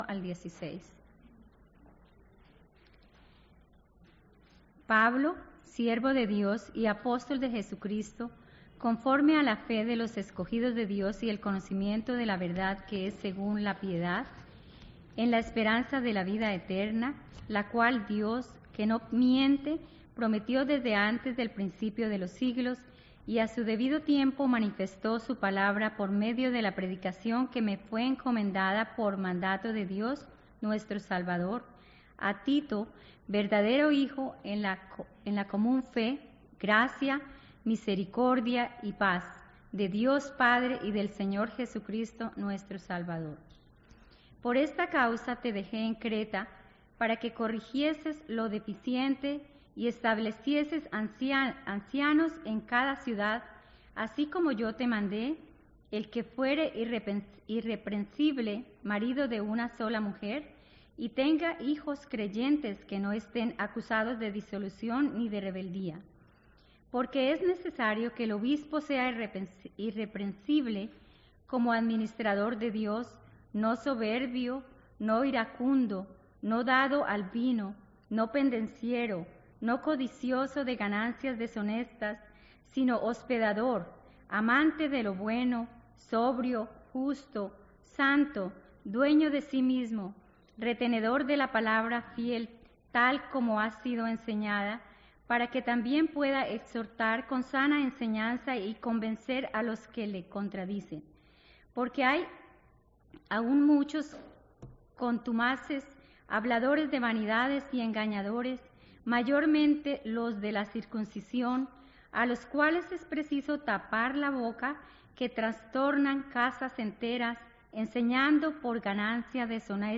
Mensajes bíblicos en español | First Baptist Church of Flushing
Current Sermon